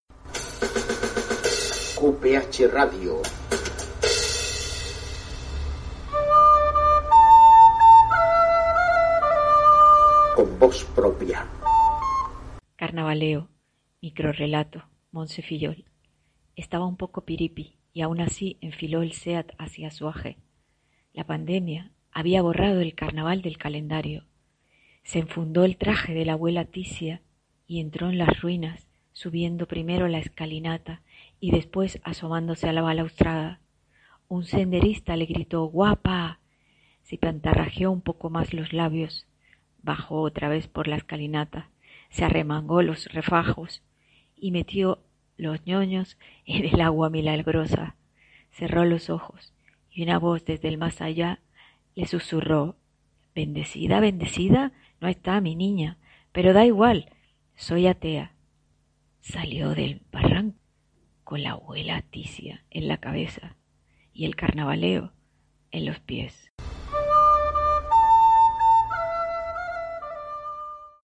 CON VOZ PROPIA “Carnavaleo” Microrrelato narrado por su autora